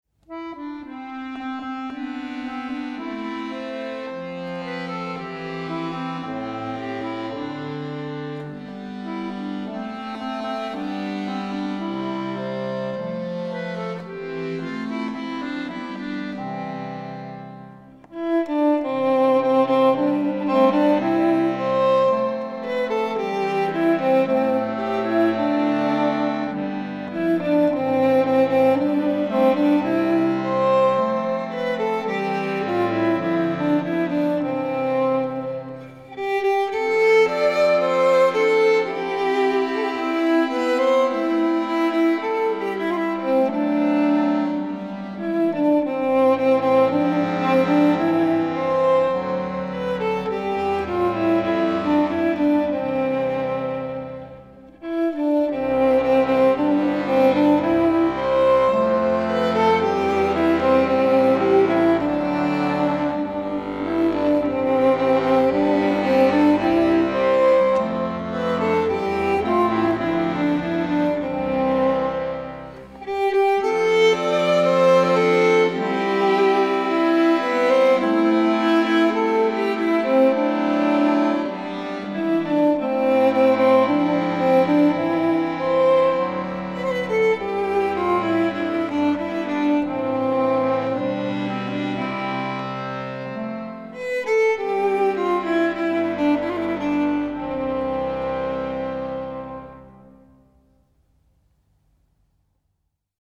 • Se set summer af sol over engen (violin&harmonika) (S. Foster)